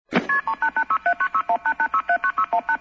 Section#1-Sweepers, sound effects
All tracks encoded in mp3 audio lo-fi quality.
fx dial loop